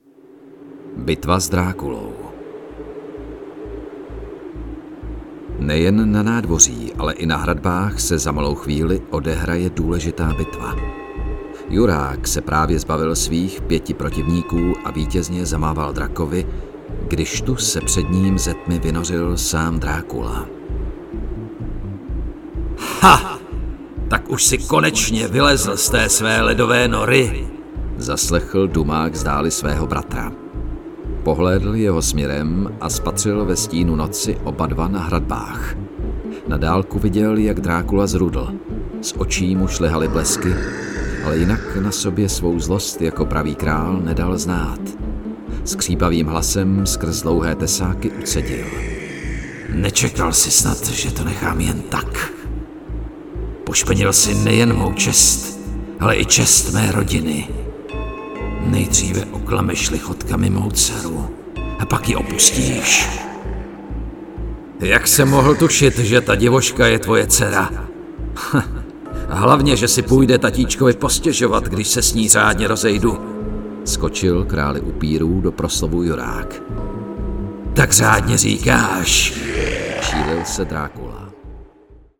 Vzhledem k sedmileté praxi v divadelní činohře jsem schopen s hlasem pracovat a přizpůsobit jej Vašim přáním a požadavkům.
Ačkoliv jsem krom výše zmíněného natočil i celou řadu produktových videí, voiceoverů, pracoval jsem mimo jiné i pro Lenovo, Office Depot a další, primárně se specializuji na čtený, mluvený projev (dabing, dokumentární filmy, audioknihy).